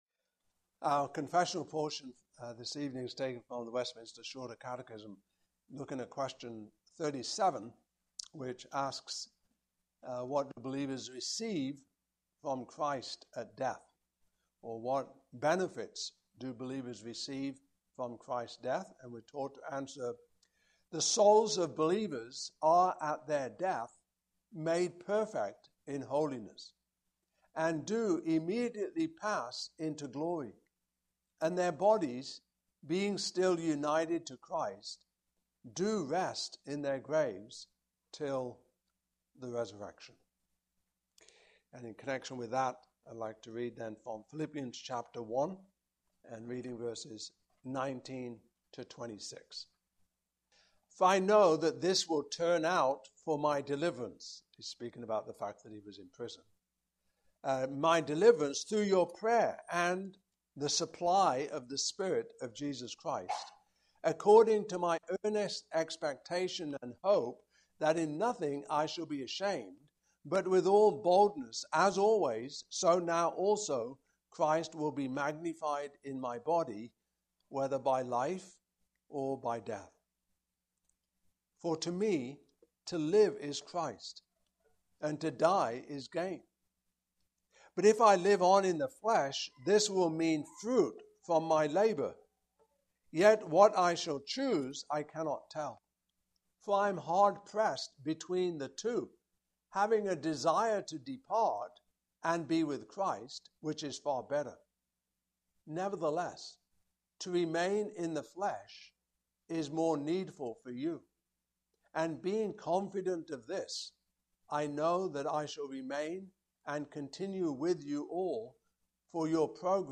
Passage: Philippians 1:19-26 Service Type: Evening Service Topics